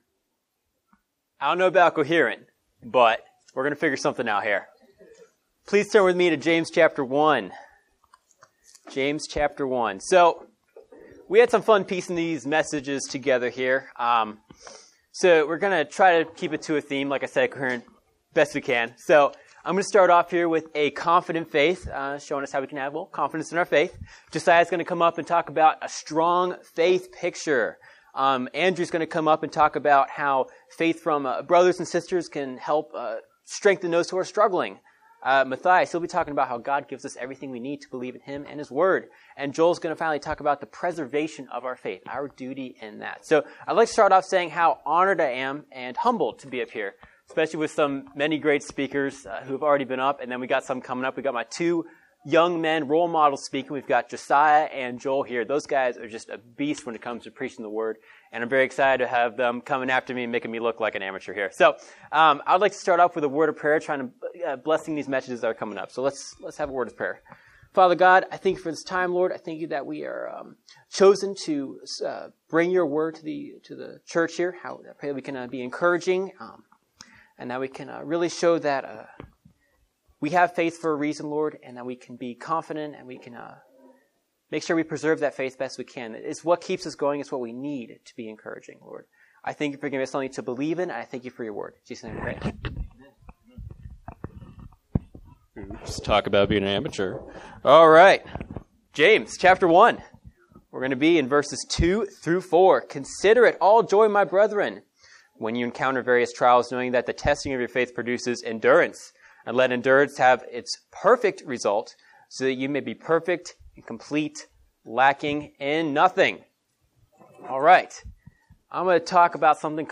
Virginia Family Camp 2024
Rapid-fire preaching